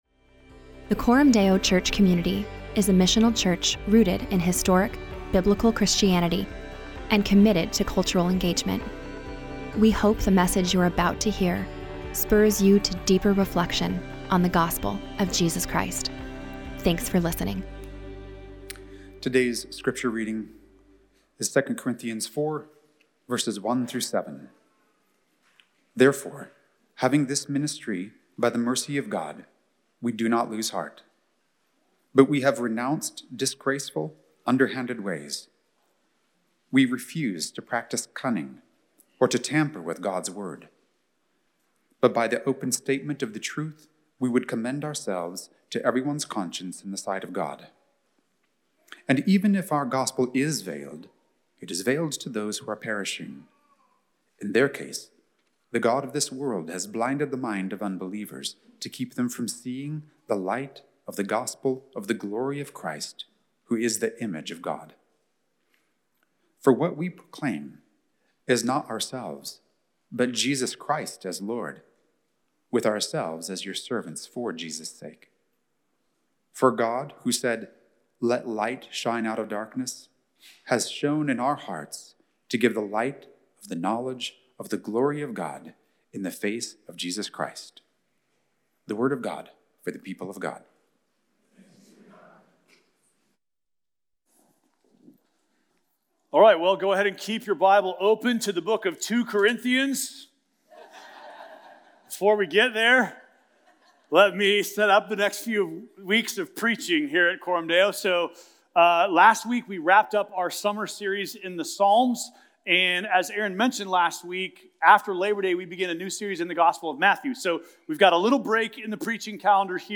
Preach It Teach It Sermon Audio Podcasts